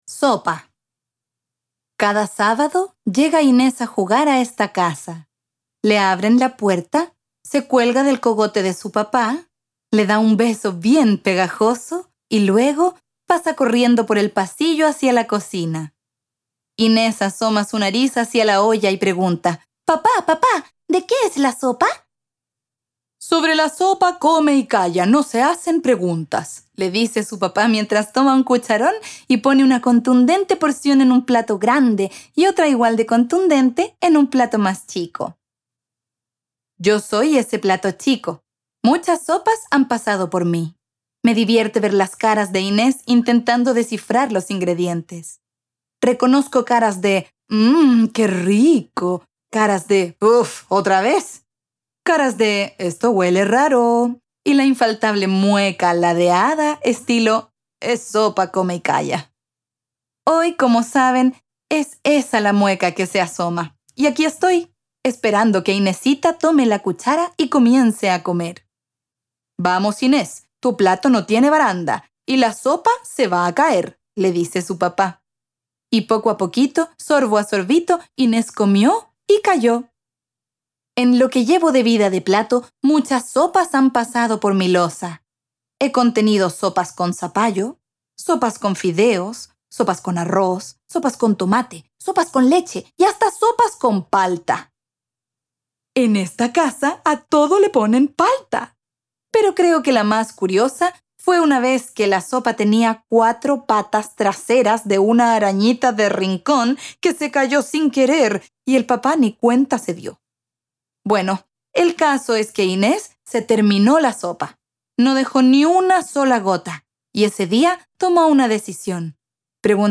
Audiocuento